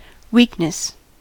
weakness: Wikimedia Commons US English Pronunciations
En-us-weakness.WAV